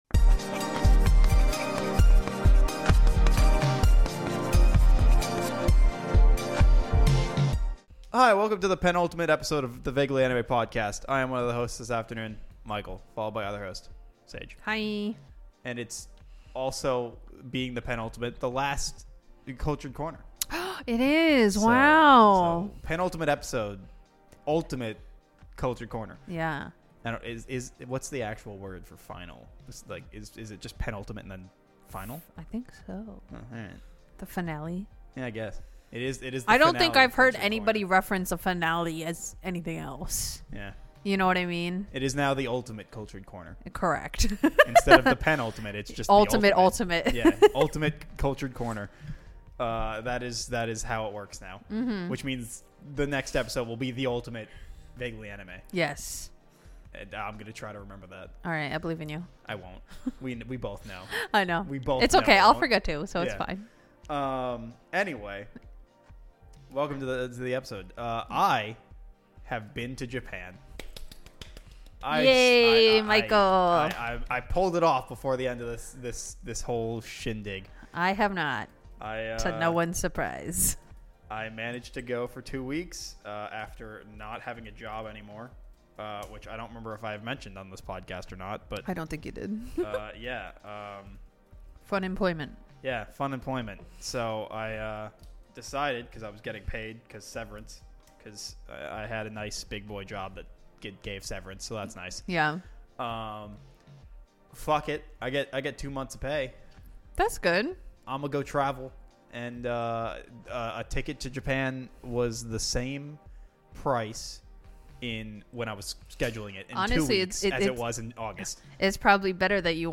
A variety podcast vaguely about anime, hosted by 5 friends in a basement.